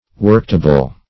Worktable \Work"ta`ble\, n.